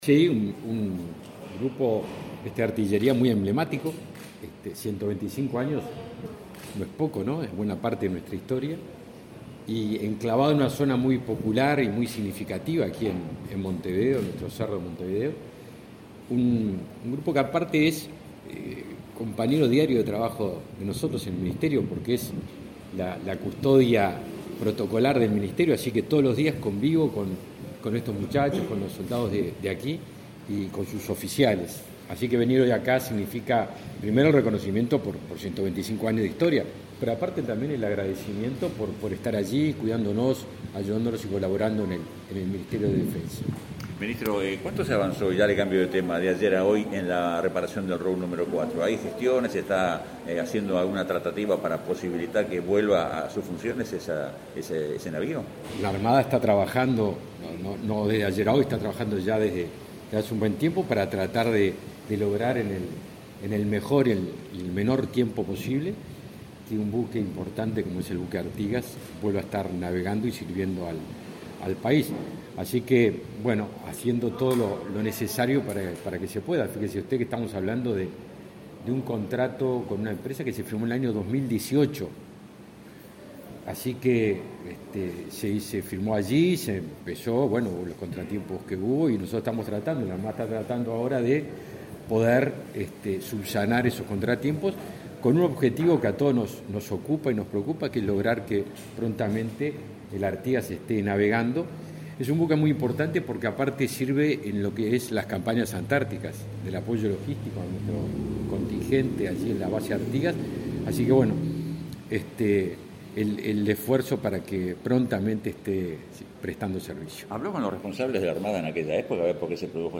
Declaraciones a la prensa del ministro de Defensa, Javier García
Declaraciones a la prensa del ministro de Defensa, Javier García 18/03/2022 Compartir Facebook X Copiar enlace WhatsApp LinkedIn El ministro de Defensa Nacional, Javier García, dialogó con la prensa luego de participar de la conmemoración del 125.° aniversario del Grupo de Artillería n.°1.